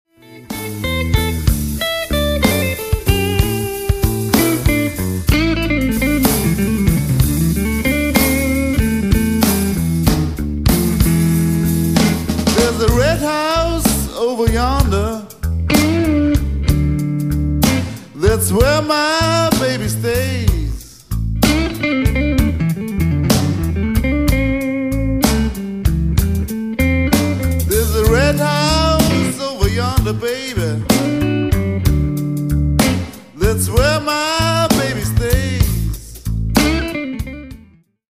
Recorded at Electric Sounddesign Studio, Linz/Austria 1999.
guitars, lead vocals
bass, vocals
drums